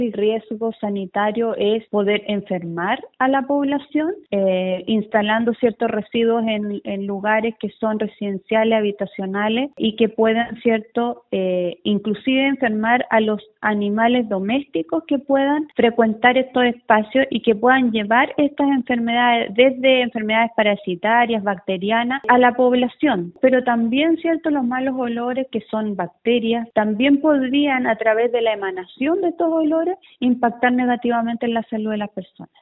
A su vez la Seremi de Salud, Karin Solís, explicó los riesgos sanitarios a los que está expuesta la comunidad de Puerto Montt, pudiendo enfermar los vecinos e incluso a los animales domésticos, quienes “pueden llevar estas enfermedades parasitarias o bacterianas a la población, pero también estos malos olores, que son bacterias, también podrían impactar negativamente en la salud de las personas”.